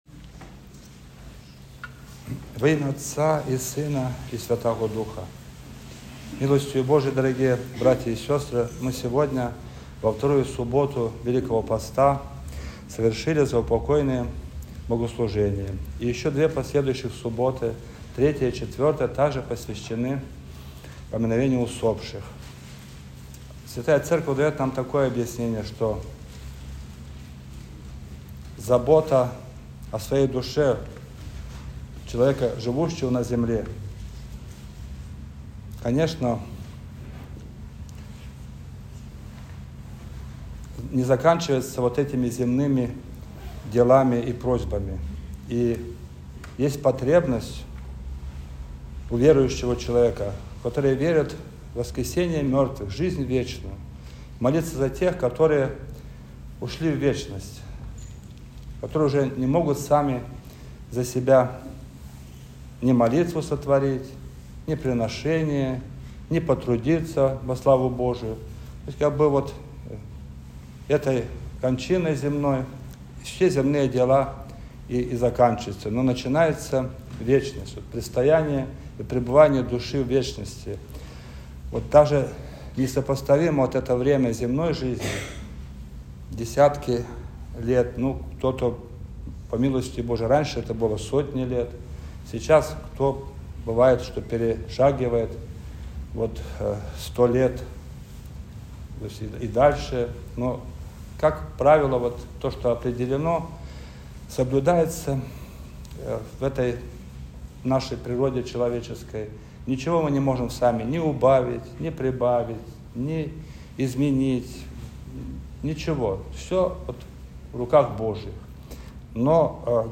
Проповедь
после Божественной литургии